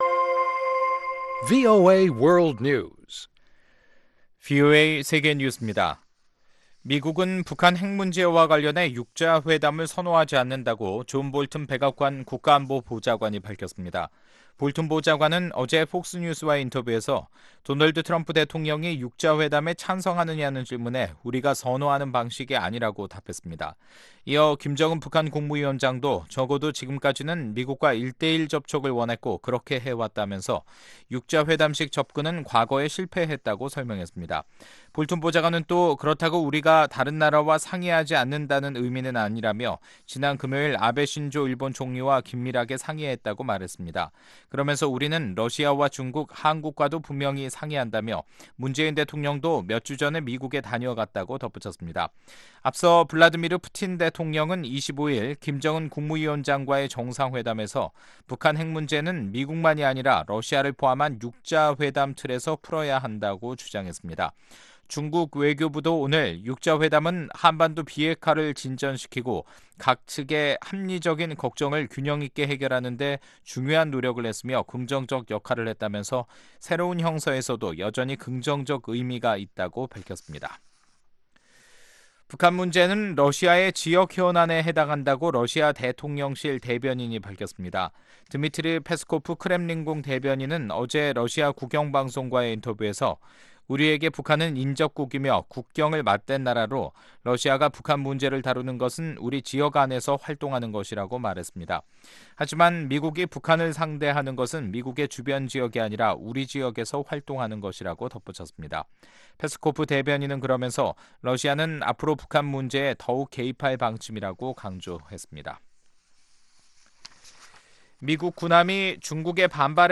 VOA 한국어 간판 뉴스 프로그램 '뉴스 투데이', 2019년 4월 29일 2부 방송입니다. 미국은 5자회담을 선호하지 않으며 트럼프 대통령은 여전히 김정은 북한 국무위원장과의 정상회담을 기대하고 있다고 존 볼튼 백악관 국가안보보좌관이 말했습니다. 진전 기미가 없는 북 핵 협상의 시발점은 실무진 간 대화 재개에 달려있다고 미국의 전직 관리들이 밝혔습니다.